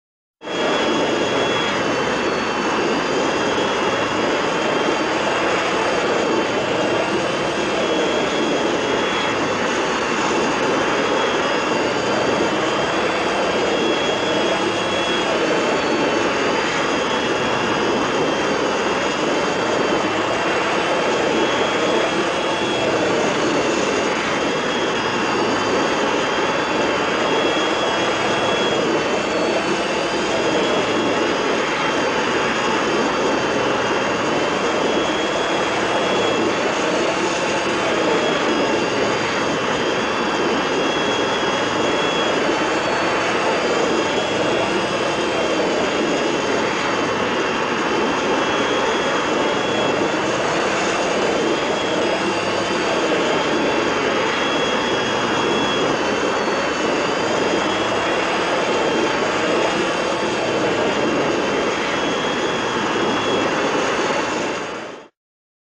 BSG FX - Viper - In flight, steady
BSG_FX_-_Viper_-_In_flight2C_steady.wav